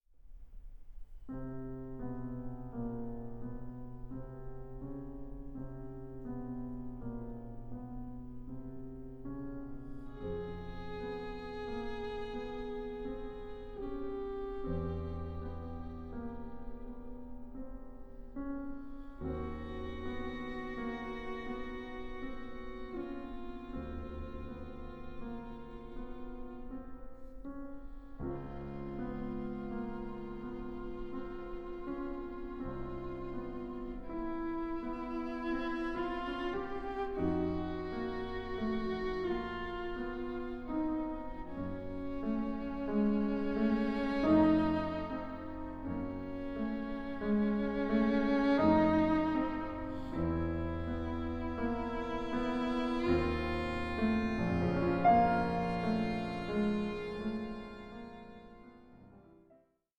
for violin, piano and string quartet